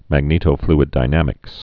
(măg-nētō-flĭd-dī-nămĭks)